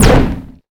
energy_blast_small_03.wav